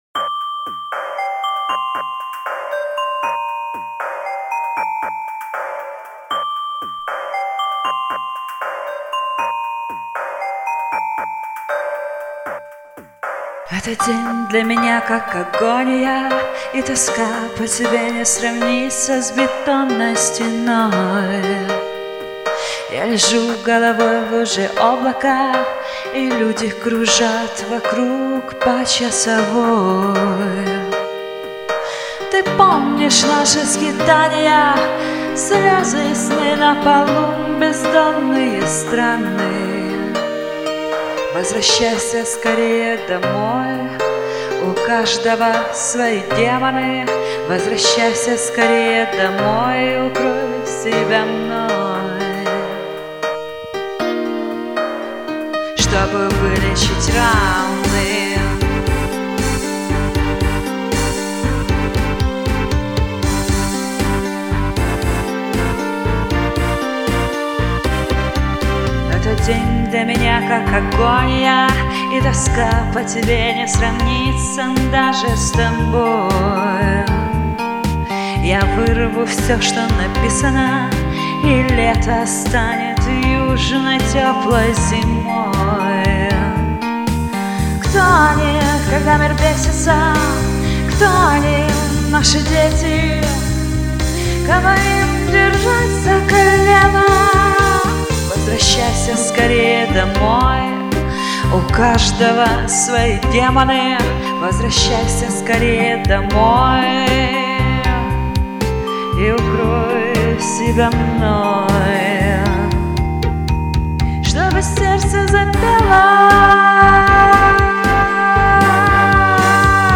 Женский рок - особая категория...........